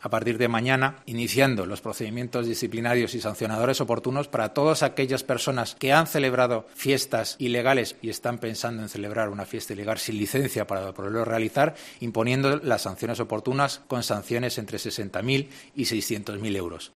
Así lo anunciaba en una rueda de prensa el Delegado de Área de Desarrollo Urbano, Mariano Fuentes, incidiendo en aquellos locales que no tienen licencia para realizar las actividades que llevan a cabo, y que encima son ilegales al no cumplir con la normativa sanitaria.